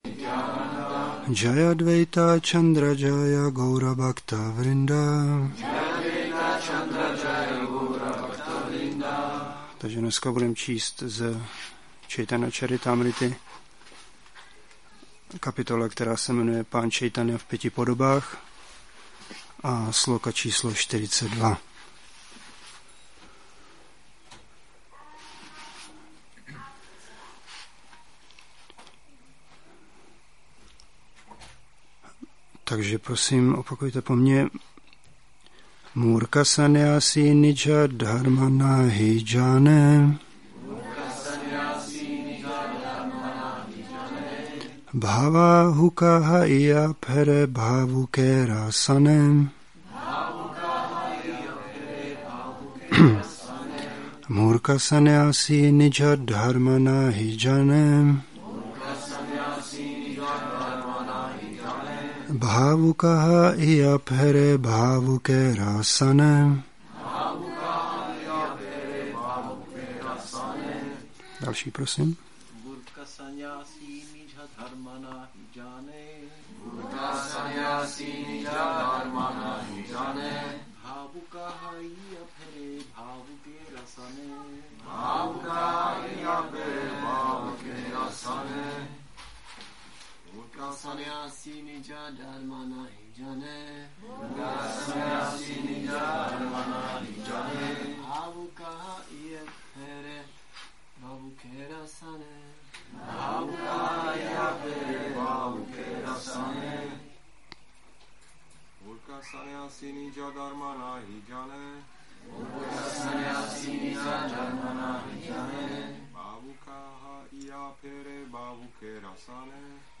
Přednáška CC-ADI-7.42